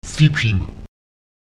Lautsprecher cipim [ÈTipim] beschleunigen (schnell machen)